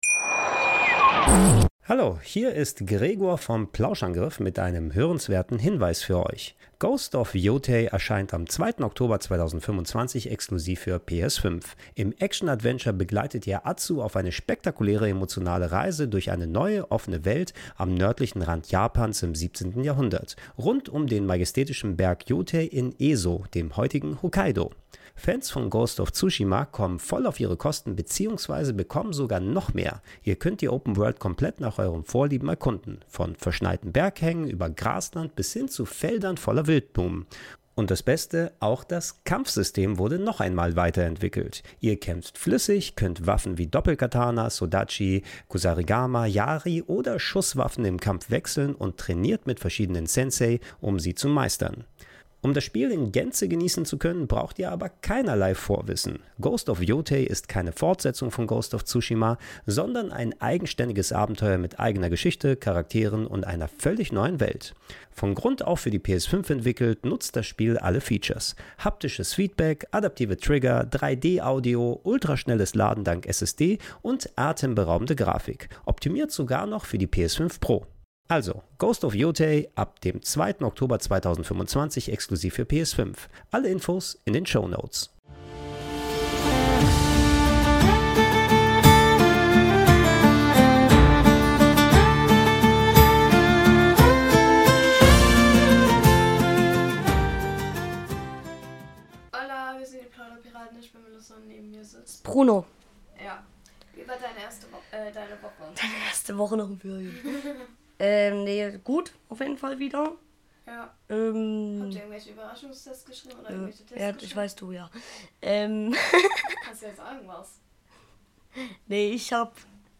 In dieser Folge wird viel gelacht und der ein oder andere Witz gemacht.